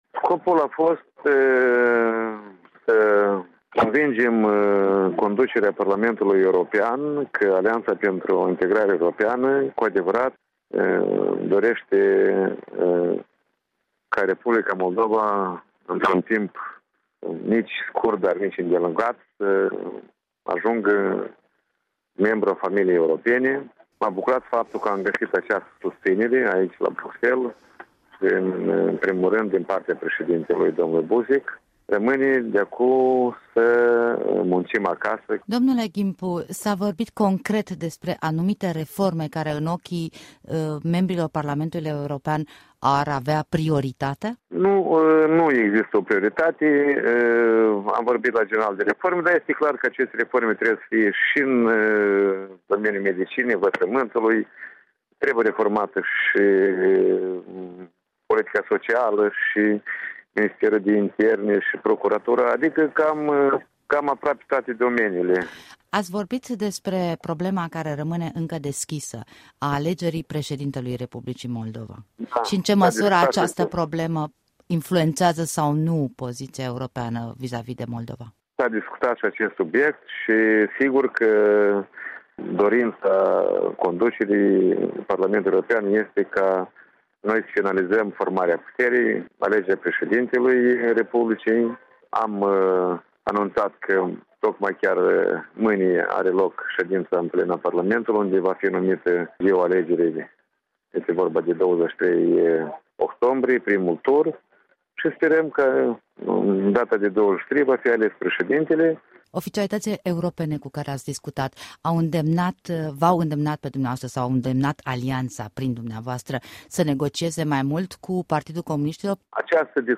Interviu la Bruxelles cu președintele interimar Mihai Ghimpu